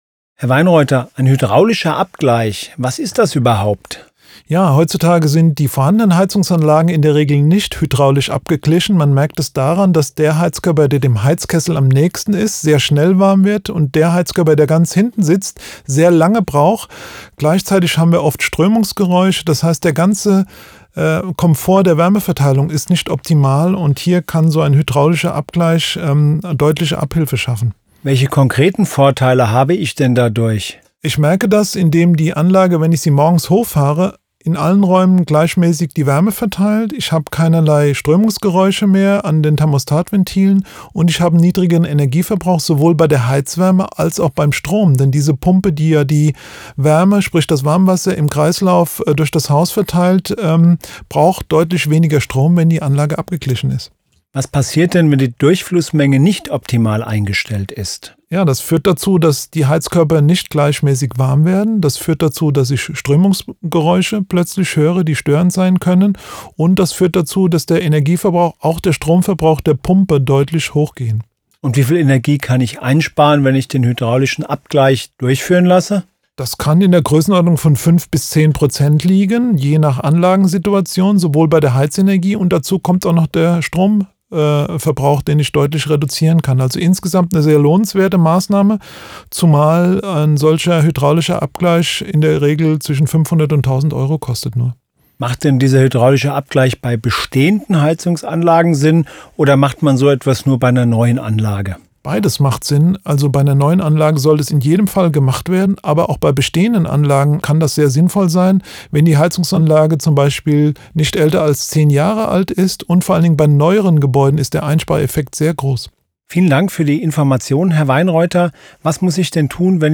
Interview zu wichtigen Energiethemen von A wie Atmende Wände über K wie Kellerdämmung bis W wie Wärmepumpe.